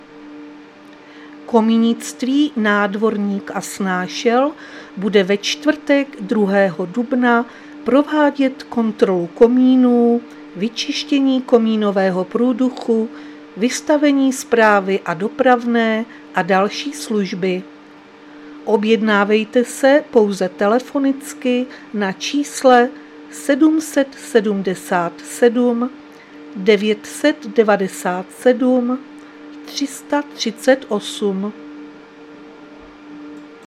Záznam hlášení místního rozhlasu 1.4.2026
Zařazení: Rozhlas